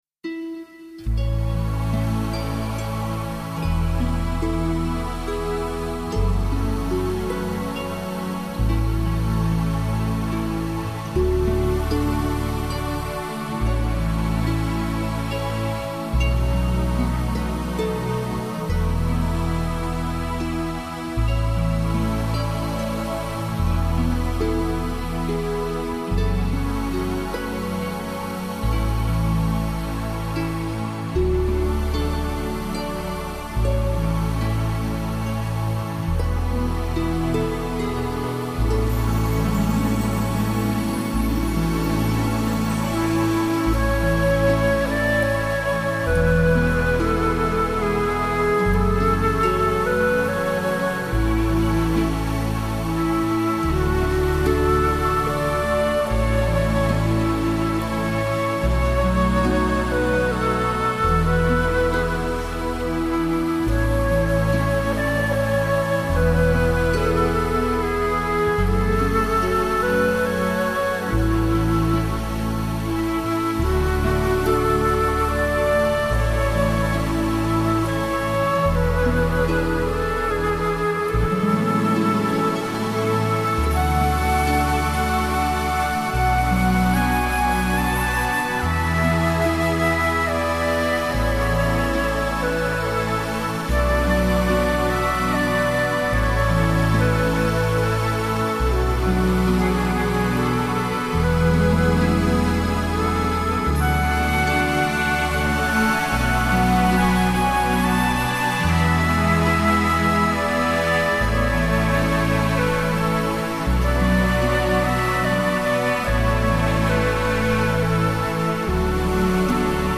音乐风格: New Age